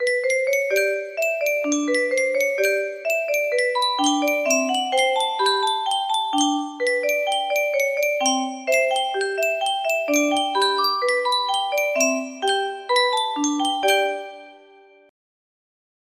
Yunsheng Music Box - Scott Joplin The Entertainer Y202 music box melody
Full range 60